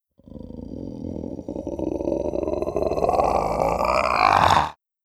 RiftMayhem / Assets / 1-Packs / Audio / Monster Roars / 23.
23. Untethered Growl.wav